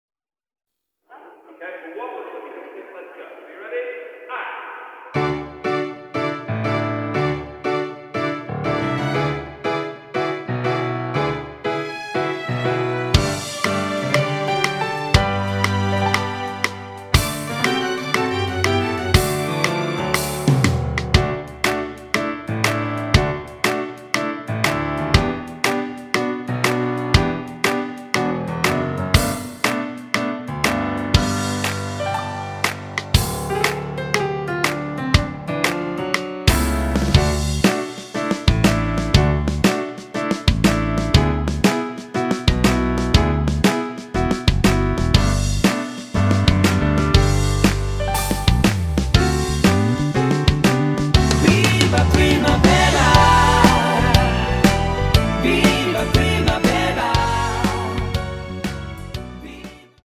원키 코러스 포함된 MR입니다.
앞부분30초, 뒷부분30초씩 편집해서 올려 드리고 있습니다.
중간에 음이 끈어지고 다시 나오는 이유는